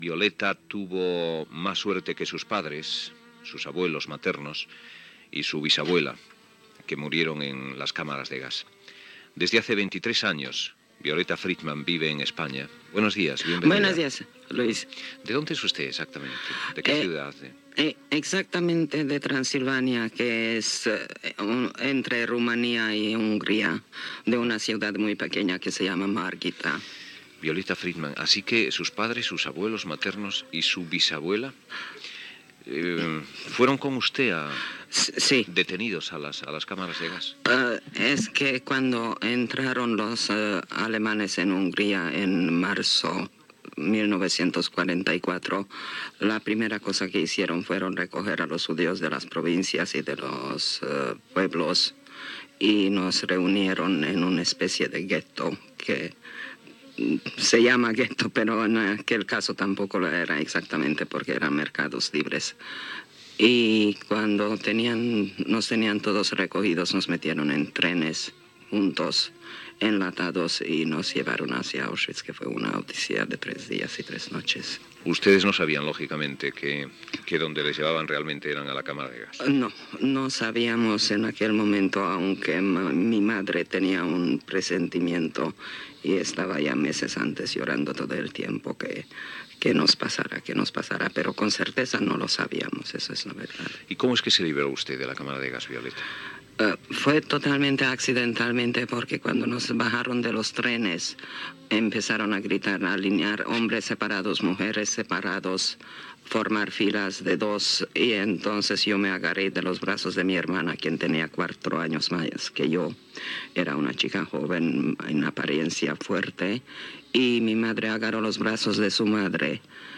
Fragment d'una entrevista a Violeta Friedman, presonera de Auschwitz
Info-entreteniment